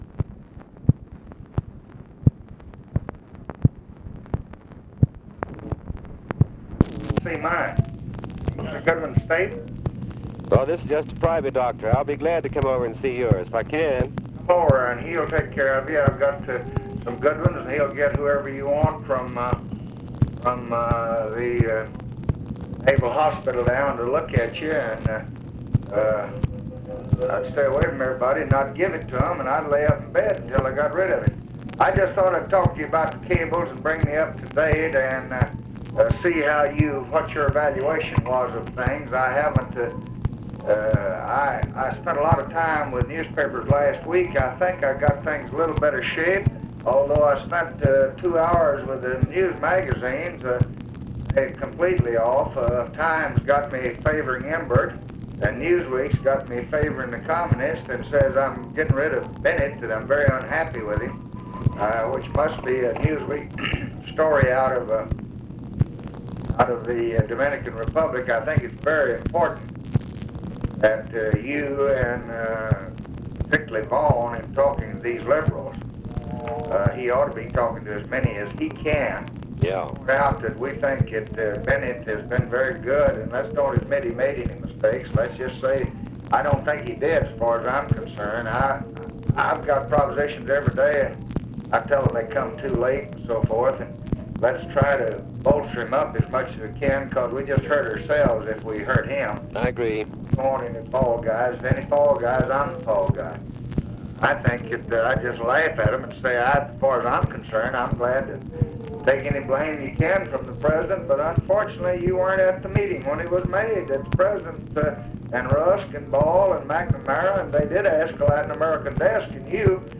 Conversation with THOMAS MANN, May 24, 1965
Secret White House Tapes